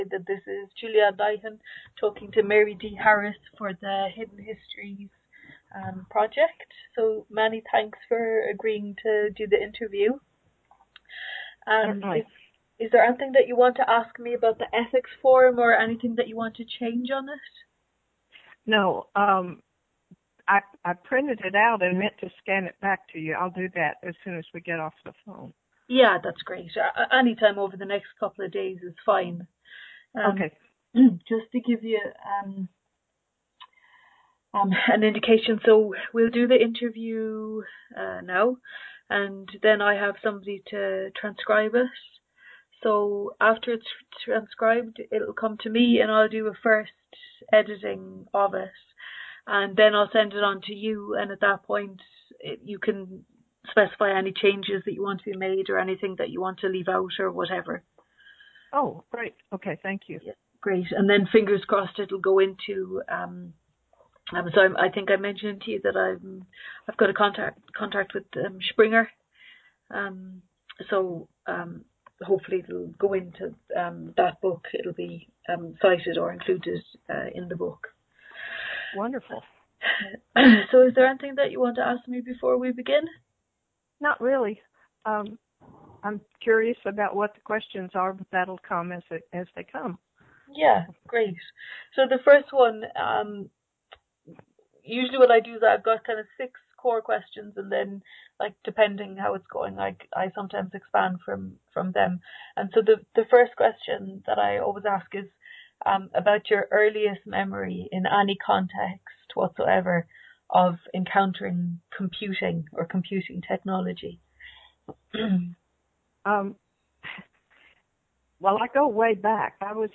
Location Skype interview
This oral history interview was conducted 3 June 2015 via skype.